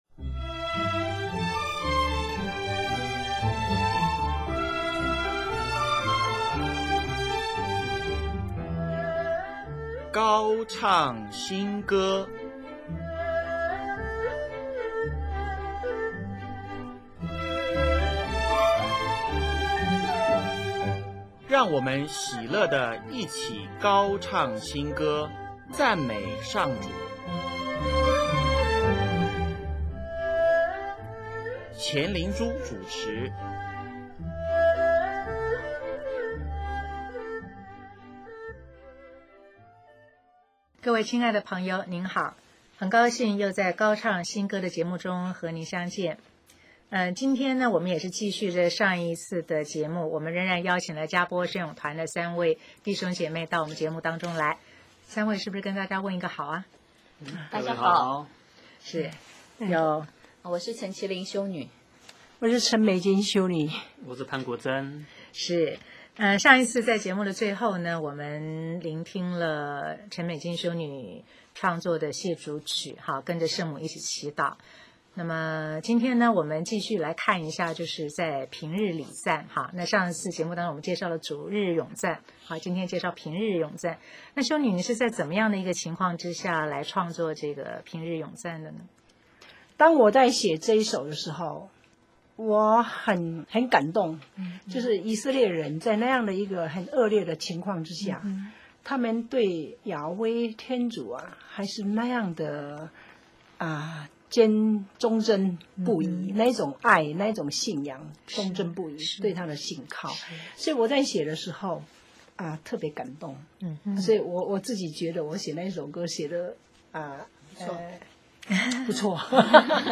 【高唱新歌】6|专访“佳播圣咏团”(二)：唱出真生命真感情